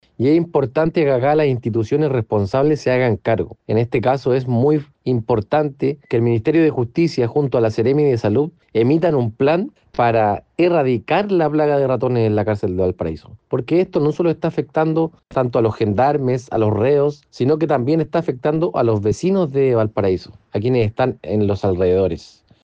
Por su parte, el concejal de Valparaíso, Vicente Celedón, valoró el fallo y cuestionó la falta de fiscalización.